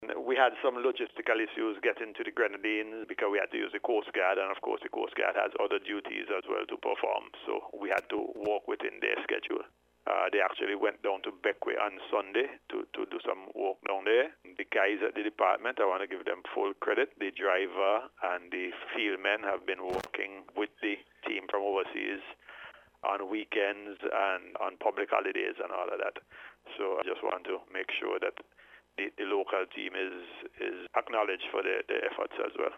Chief Surveyor Keith Francis provided an update on the exercise during an interview with NBC News.